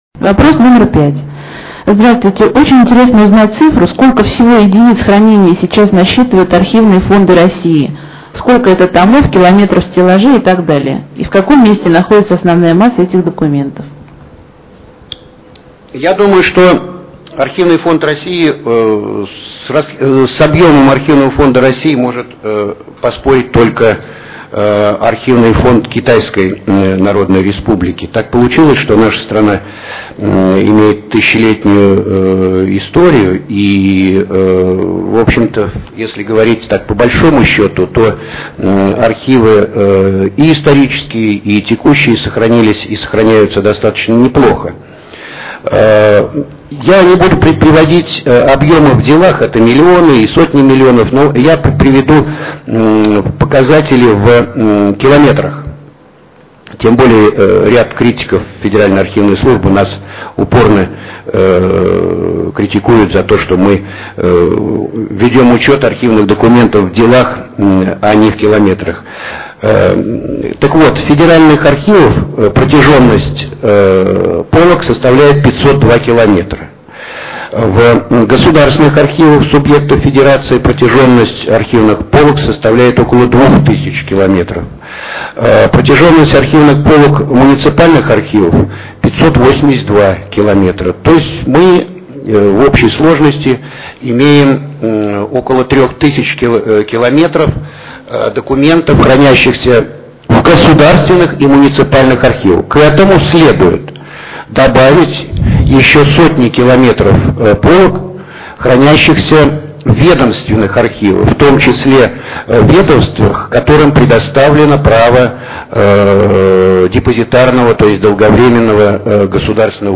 Интернет-конференция